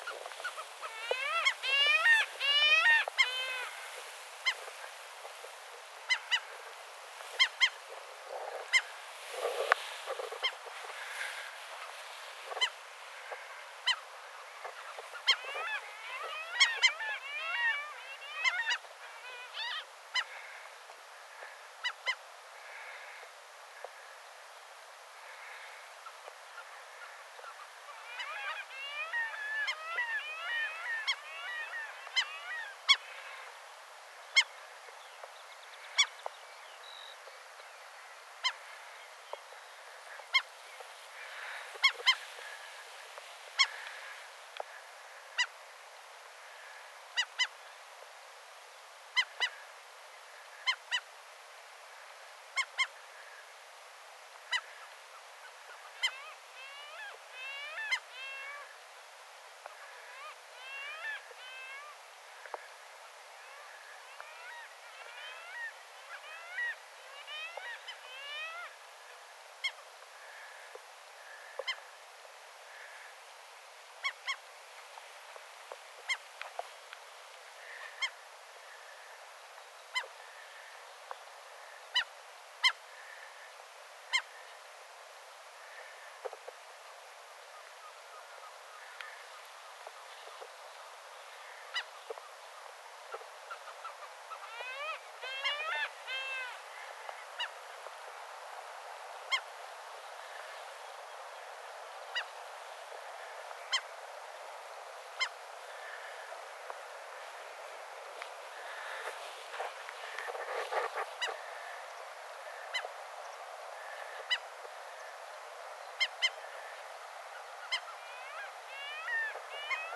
merikihujen ääntelyä
merikihujen_aantelya.mp3